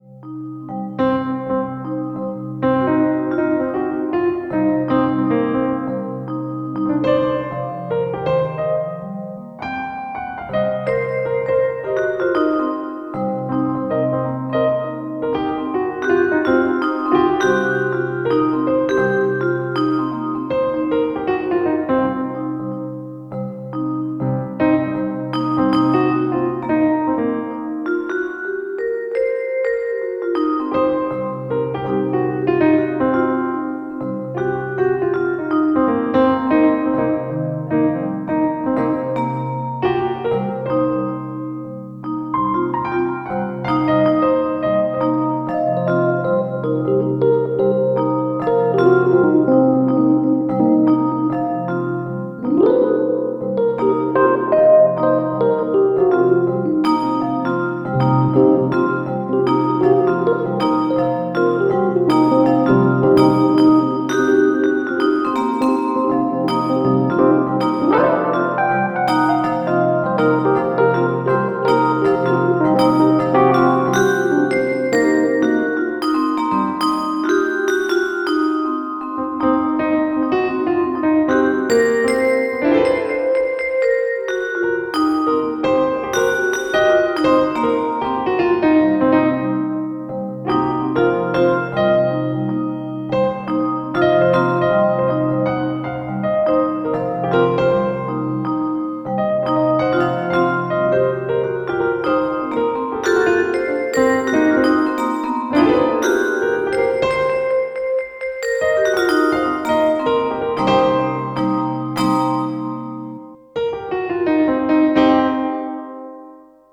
Tempo: 80 bpm / Datum: 27.03.2017